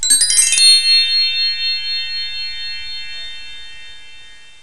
chimes.wav